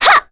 Ophelia-Vox_Attack1.wav